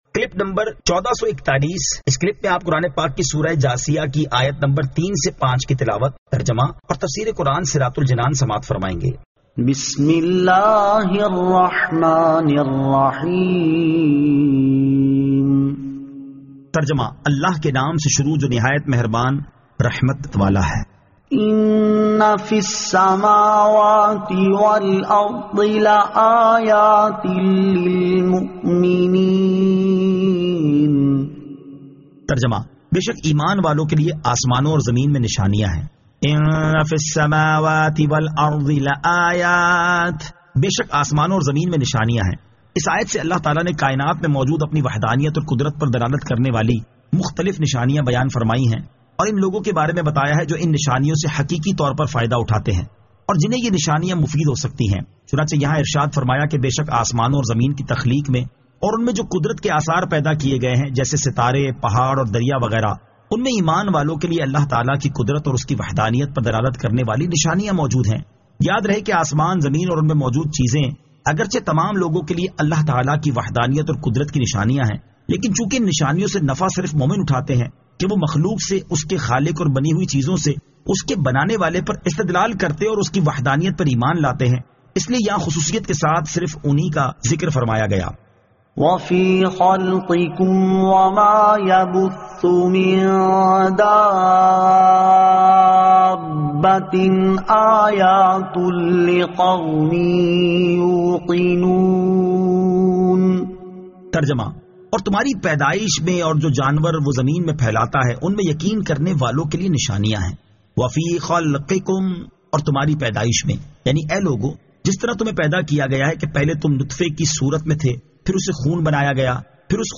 Surah Al-Jathiyah 03 To 05 Tilawat , Tarjama , Tafseer